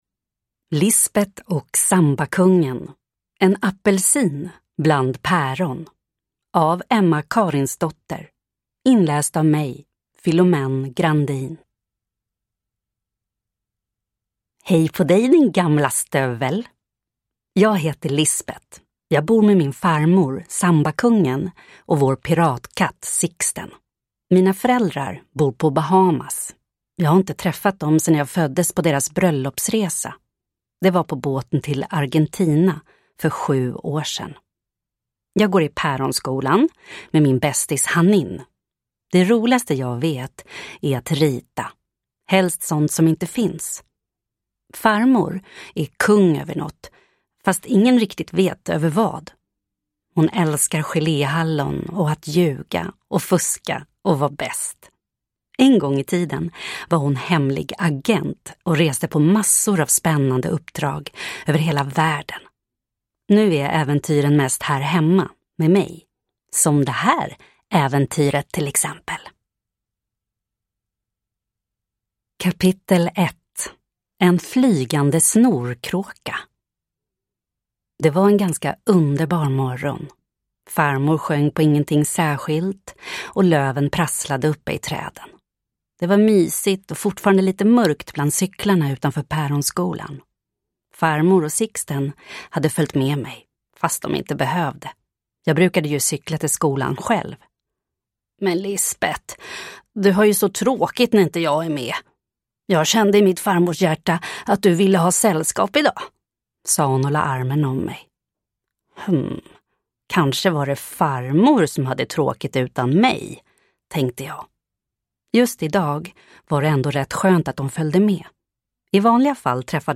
Nu kommer en kortare och lite mer lättlyssnad bok i serien.